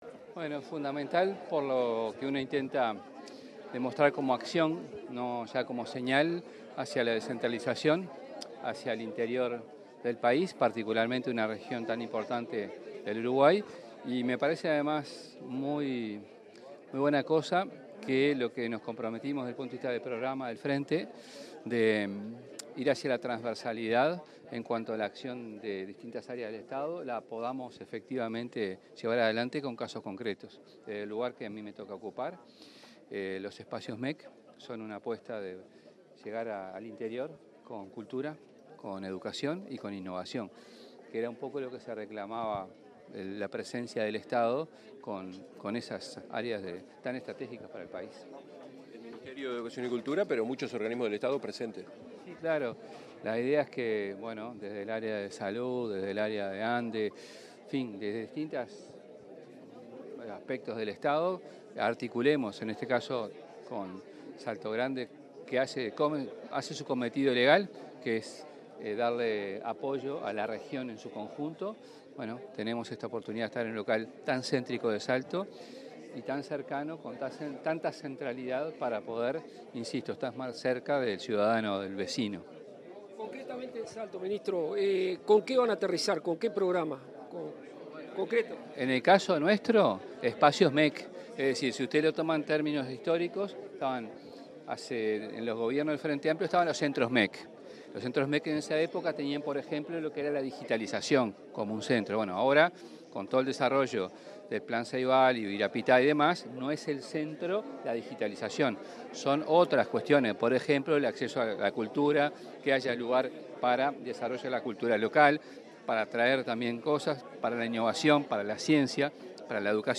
Declaraciones del ministro de Educación y Cultura, José Carlos Mahía
El titular del Ministerio de Educación y Cultura, José Carlos Mahía, asistió a inauguración de las obras de ampliación del Centro Conecta en Salto. En diálogo con los medios informativos, se refirió a la necesidad de descentralizar y acercar el Gobierno al interior del país, y enfatizó en la presencia de los Espacios MEC en estos lugares.